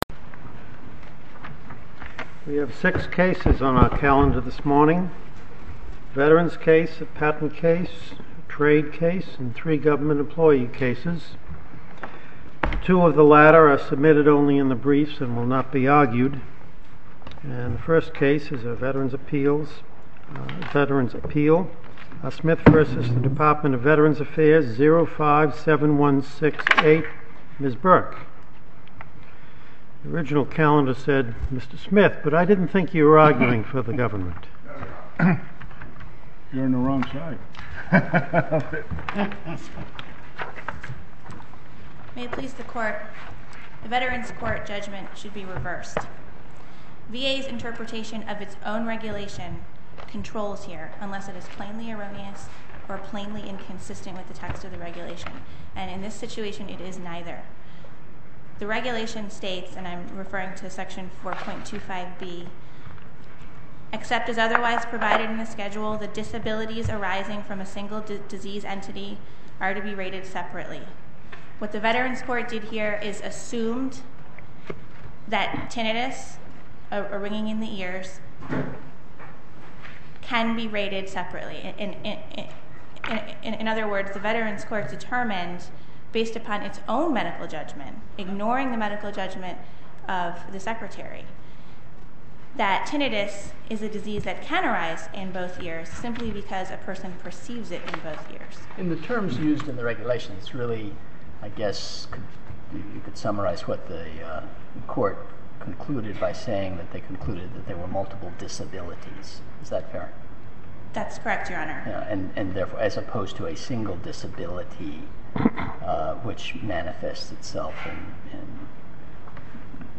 Oral argument audio posted